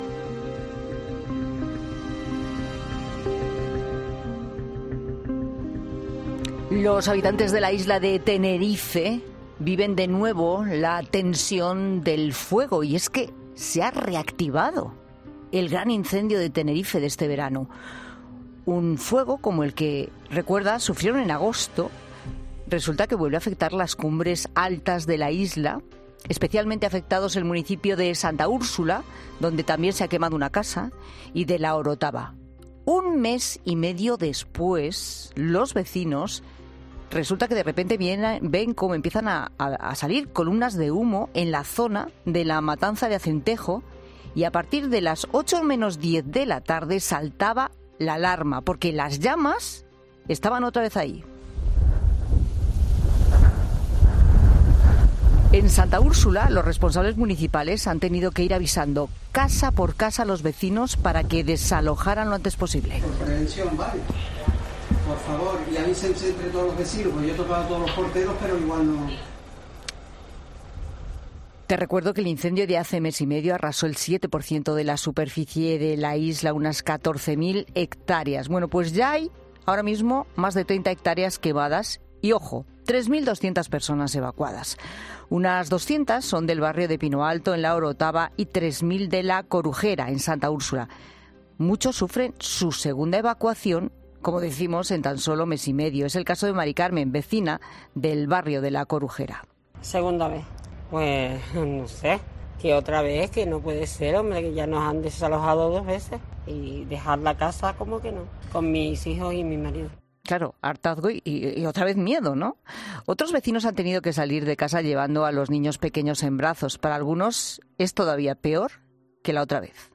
Un experto explica en 'La Tarde' cómo se producen los incendios zombi